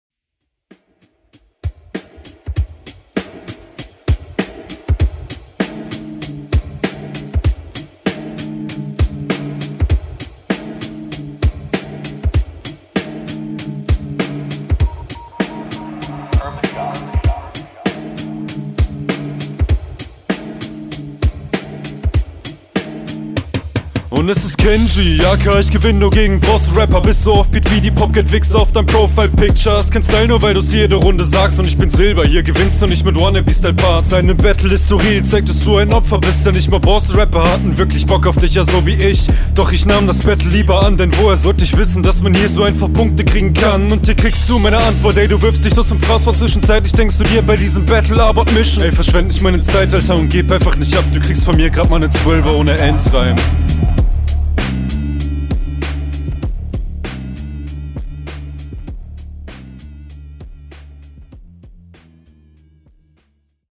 Geilen Beat gepickt..
Flow: Flow ist gut.
Flow: Super swaggy und nice zum beat geflowt.